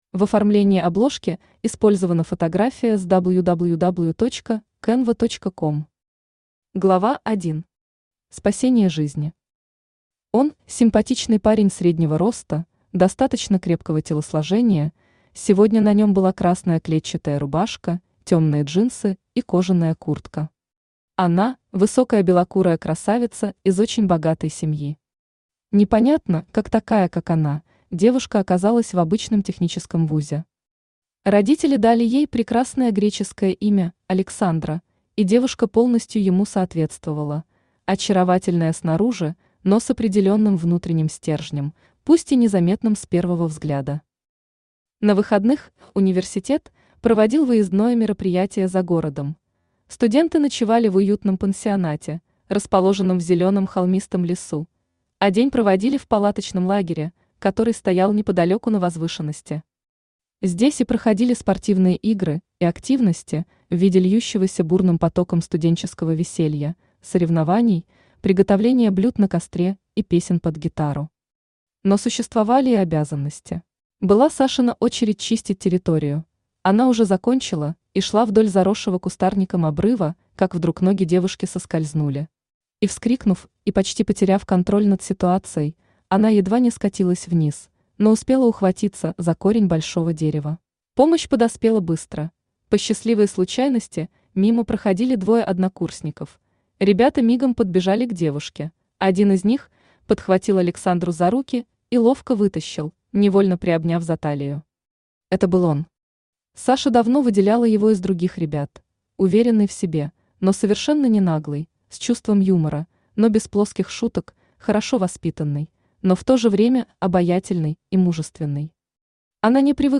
Аудиокнига Любовь без блуда | Библиотека аудиокниг
Aудиокнига Любовь без блуда Автор Антонина Ховавко Читает аудиокнигу Авточтец ЛитРес.